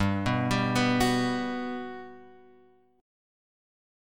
G Major 7th